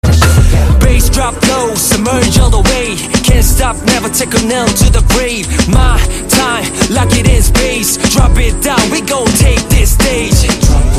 Old School Hip Hop